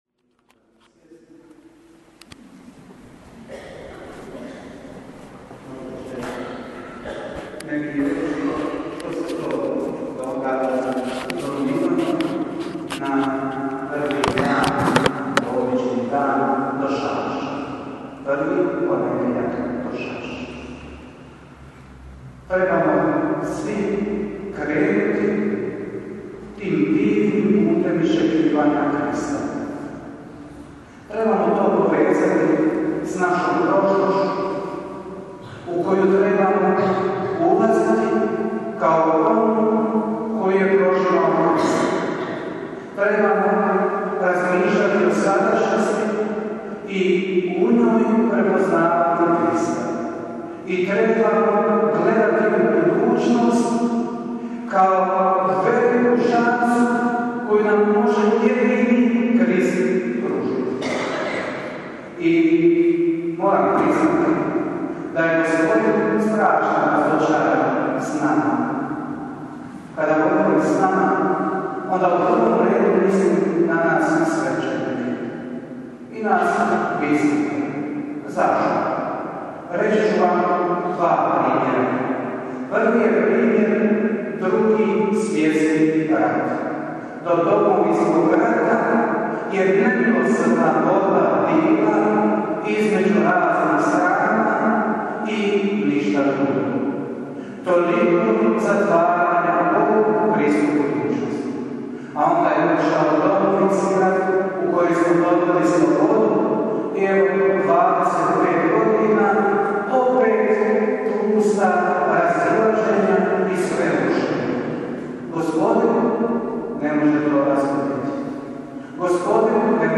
Kratka propovjed: